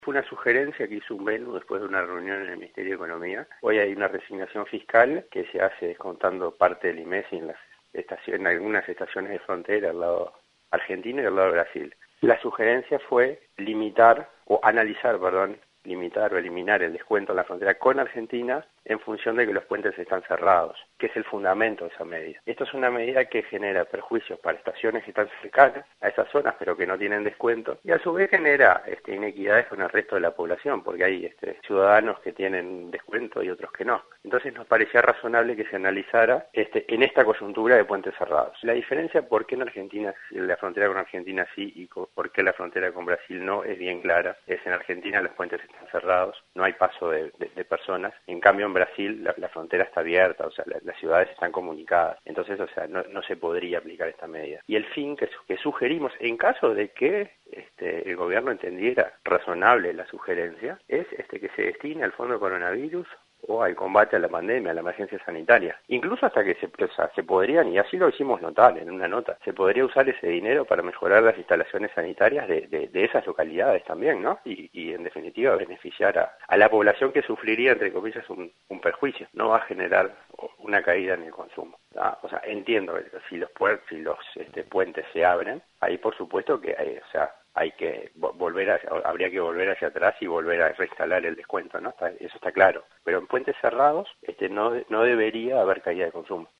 en diálogo con 970 Noticias